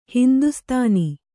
♪ hindustāni